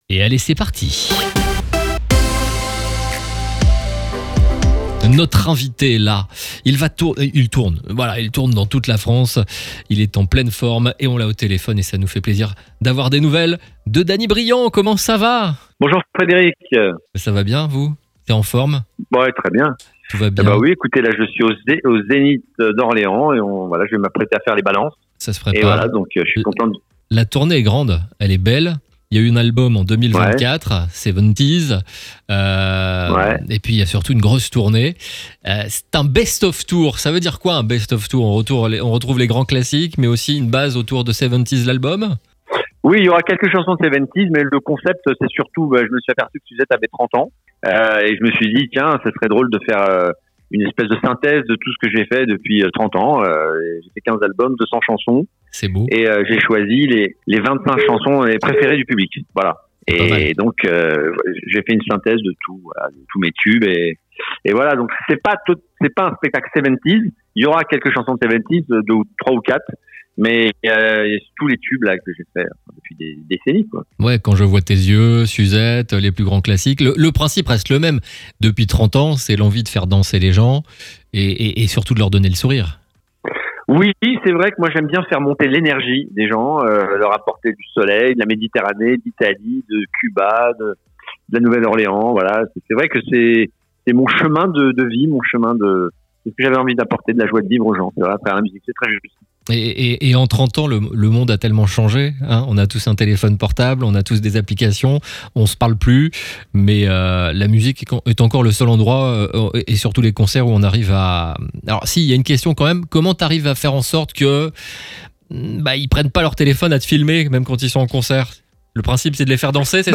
Les interviews exclusifs de RCB Radio